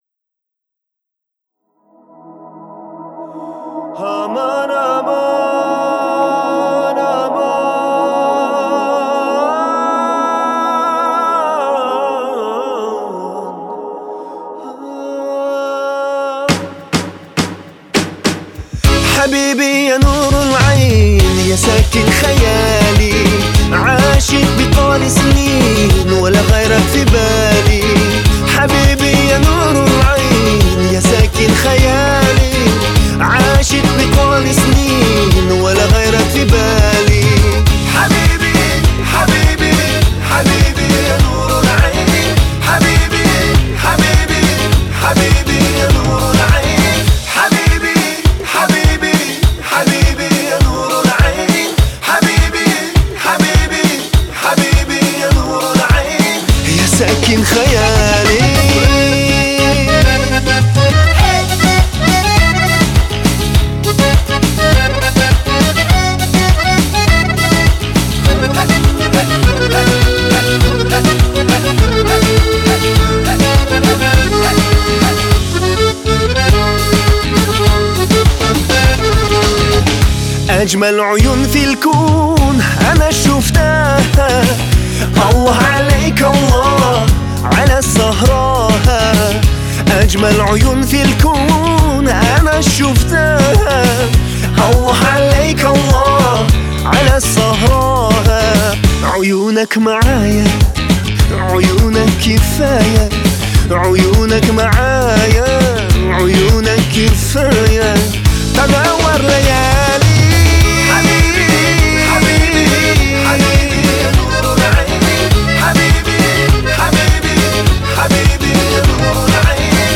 это яркий пример казахского поп-музыки